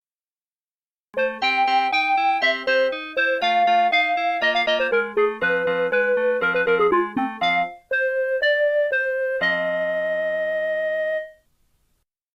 學校鐘聲投票